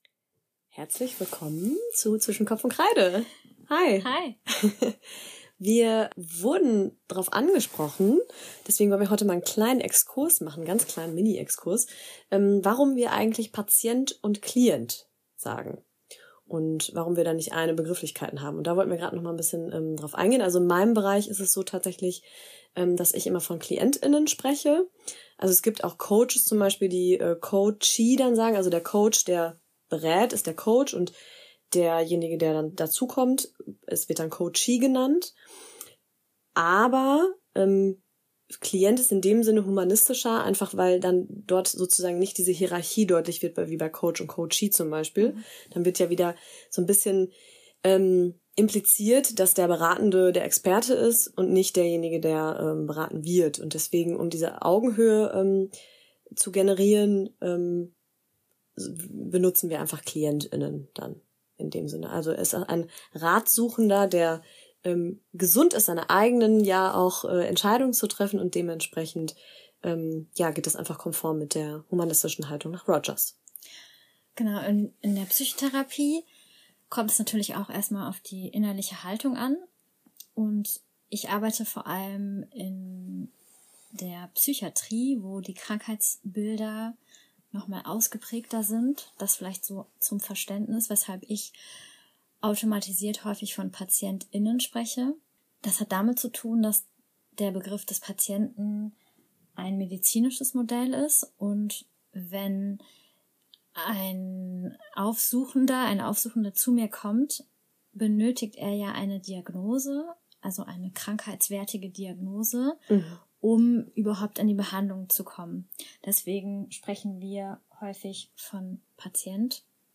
#04 Klient vs. Patient - eine Diskussion